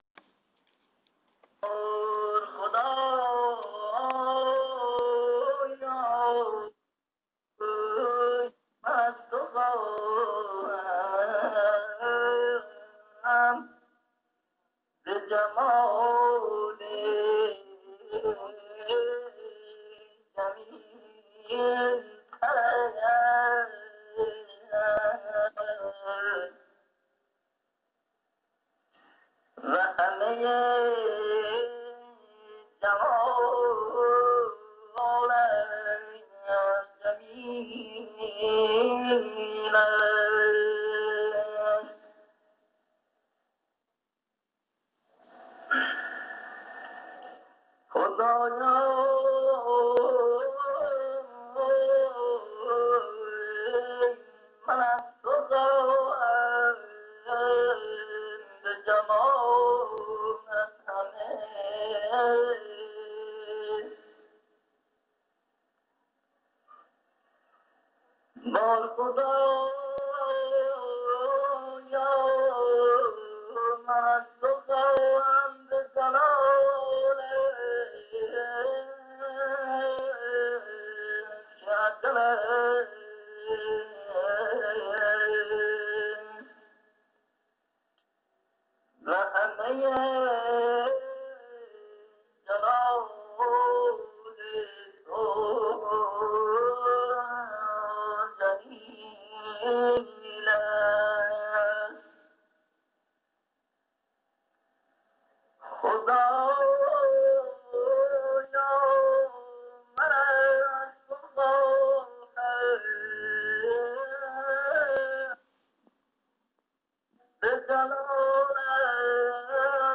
صدای ماندگار